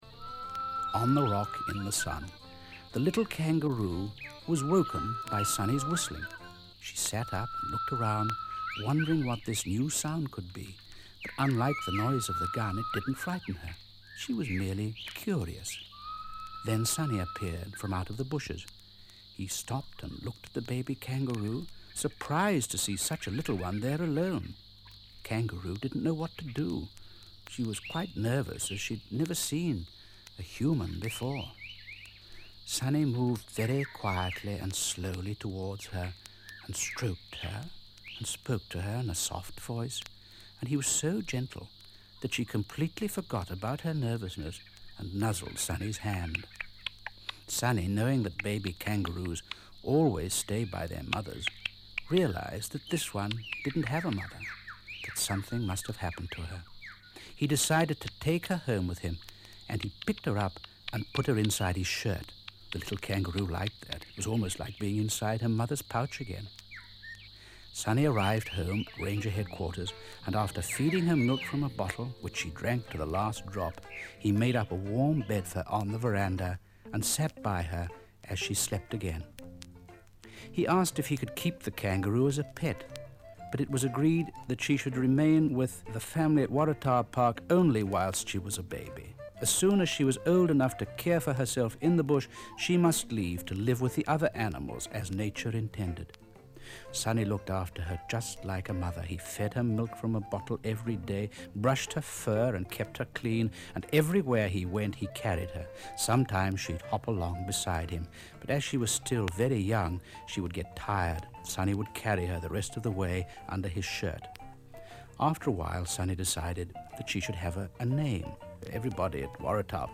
This is an excerpt from side one of a spoken word recording giving the backstory of how Skippy and Sonny first met.
Song then spoken word. relates the story of Skippy as a baby, meeting Sonny for the first time, saving Sonny by attacking a snake.